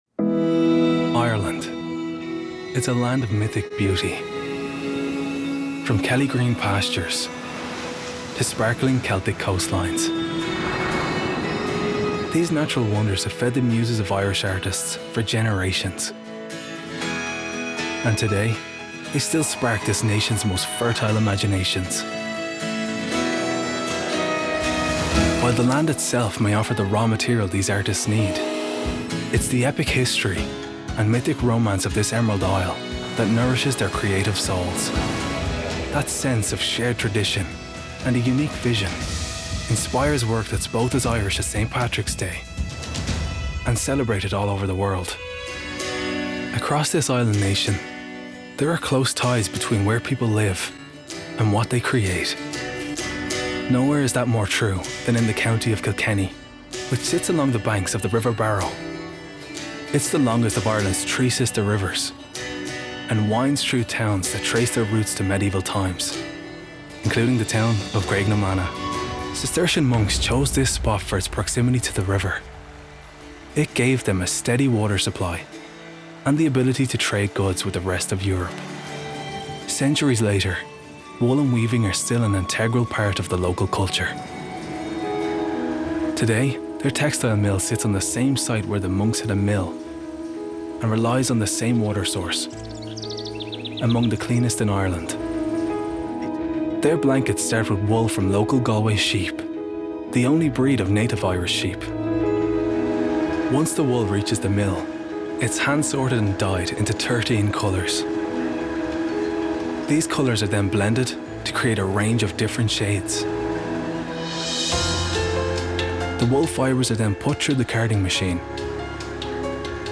Narration Showreel
Gender Male Native Accents Irish Southern Irish
Styles Friendly Warm Confident